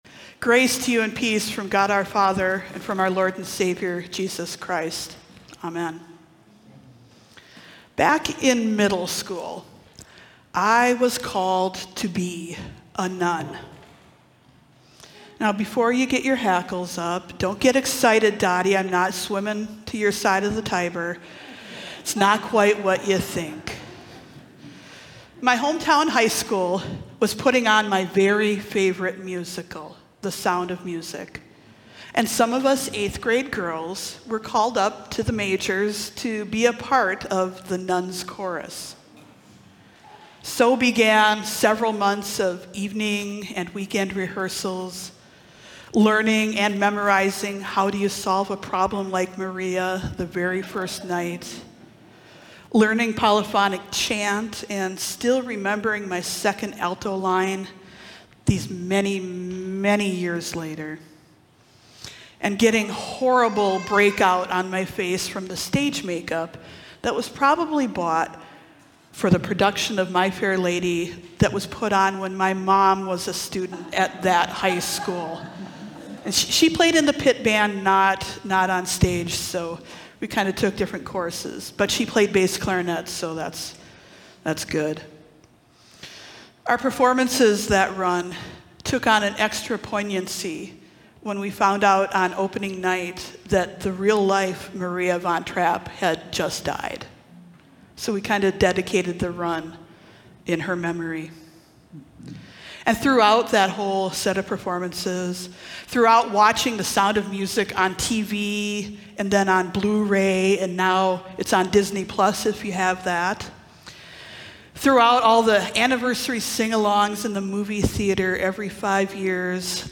Sermon from Sunday, October 19, 2025